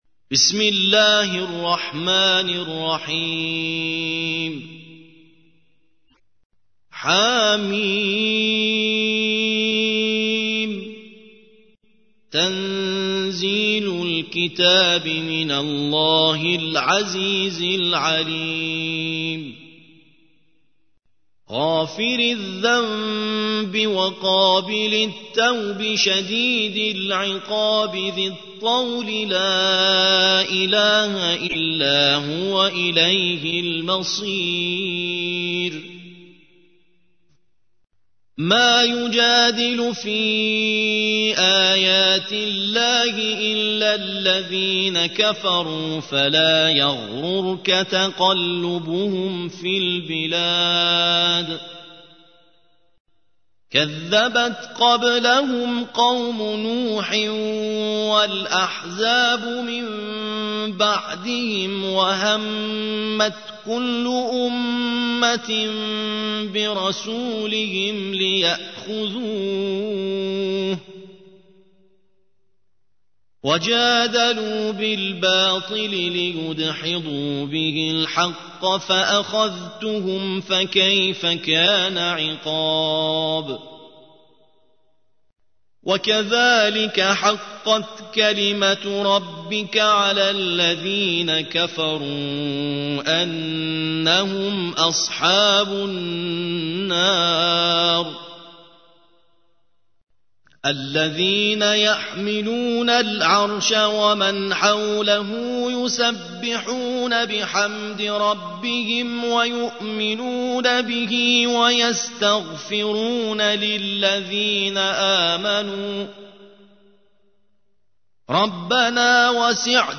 40. سورة غافر / القارئ